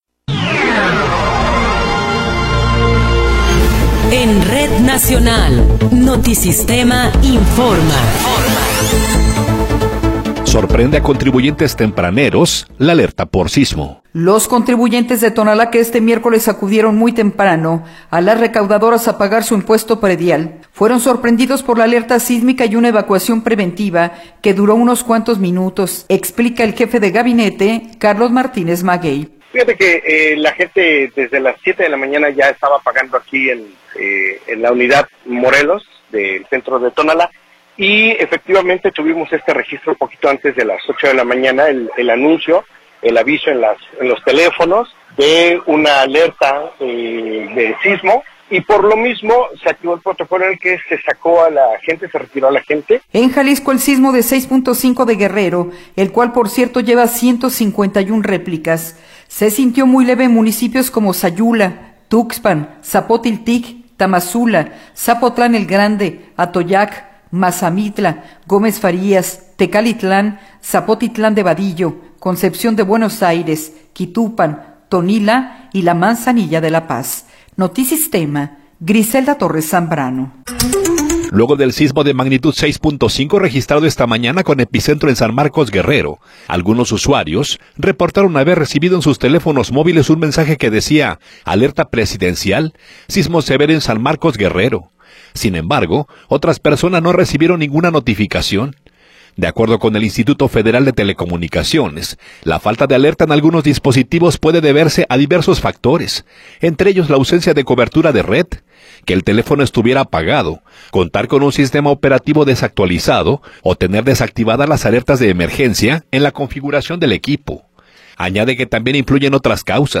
Noticiero 12 hrs. – 2 de Enero de 2026